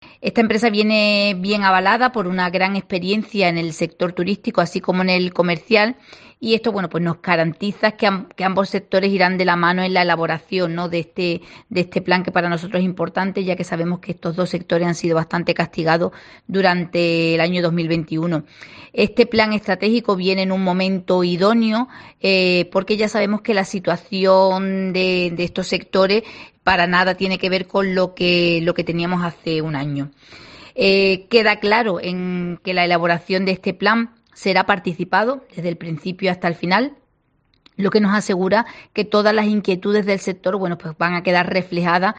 La concejala Montemayor Mures avanza el nuevo Plan de Turismo y Comercio de la ciudad